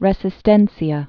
(rĕsĭ-stĕnsē-ə, rĕsēs-tĕnsyä)